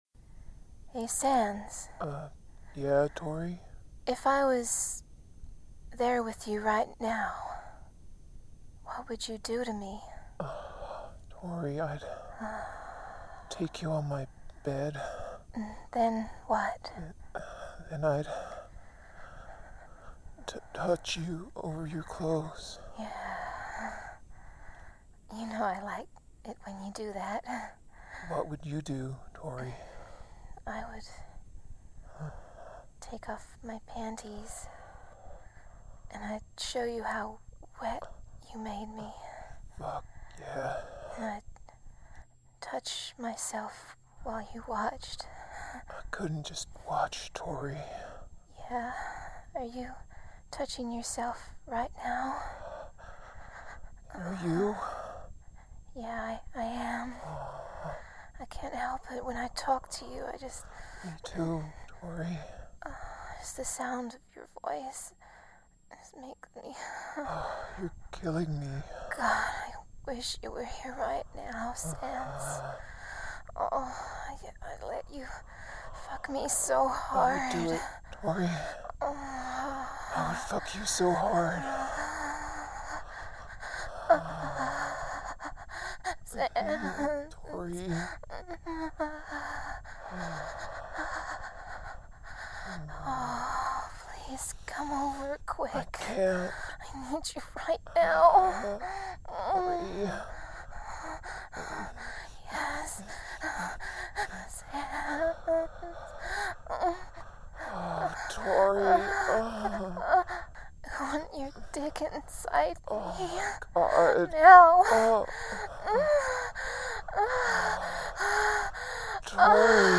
Also fear my Sans voice, FEAR IT I SAY.